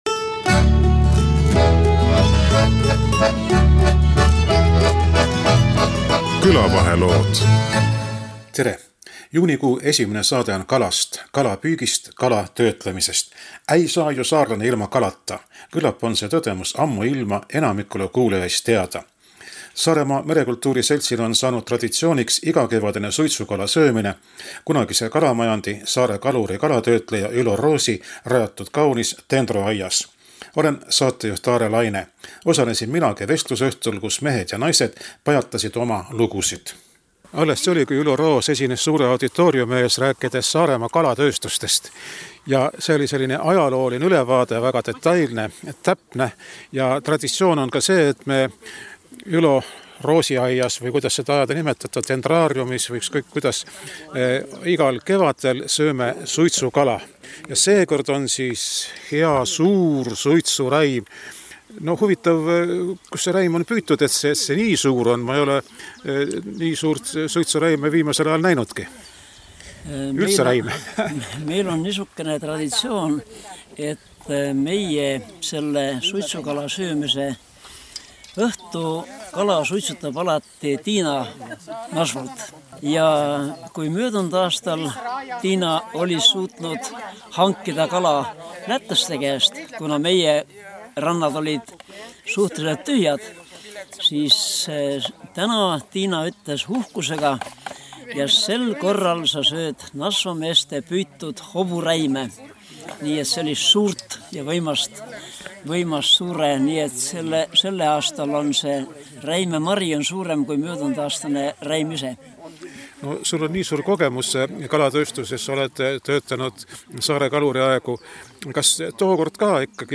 Mida seal vestlusringis pajatati, seda saavad kuulajad teada.